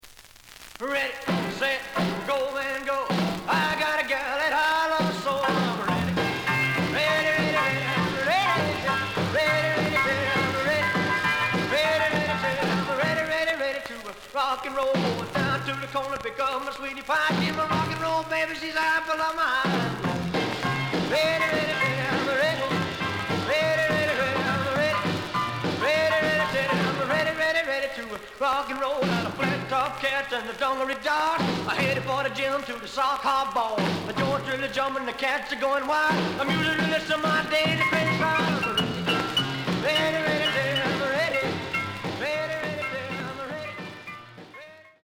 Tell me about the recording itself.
The listen sample is recorded from the actual item. Slight edge warp.